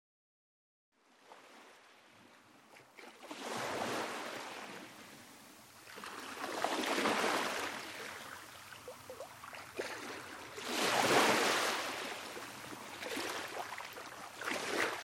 • Le bruit de fond est celui du fichier
mer-calme.mp3